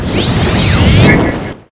osiprAltFire.ogg